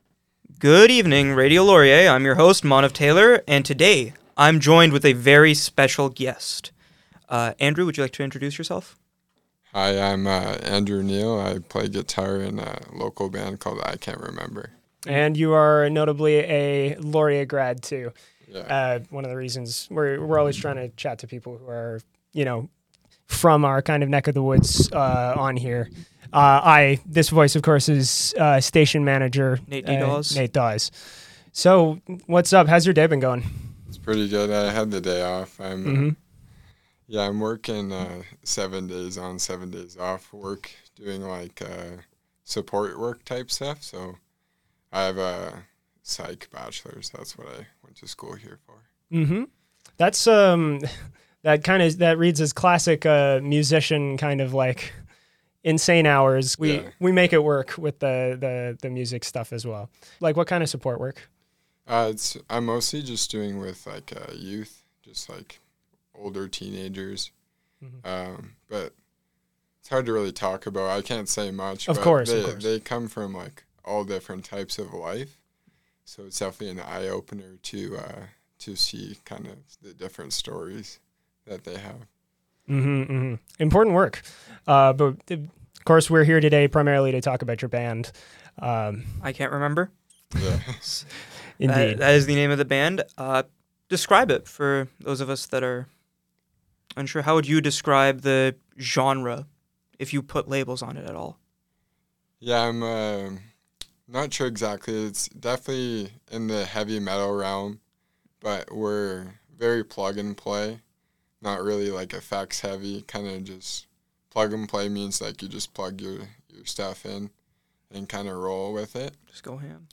Band Interviews I CAN'T REMEMBER - March 10, 2026 Mar 20 2026 | 00:26:28 1x 00:00 / 00:26:28 Subscribe Share Apple Podcasts Spotify Overcast RSS Feed Share Link Embed